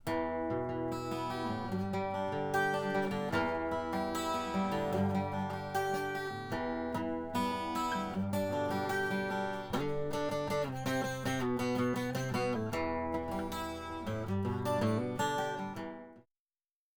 Här kommer ett looptest av DA > mikrofonförstärkare > AD.
Filerna är nivåmatchade med gainkontrollen på mickförstärkarens ingång.
Båda snuttarna är 16bitar men gitarren är samplad med 88.2kS/s, jazzlåten 44.1kS/s.
Mikrofonförstärkaren är Line Audio 8MP, omvandlare är Lynx Aurora 8.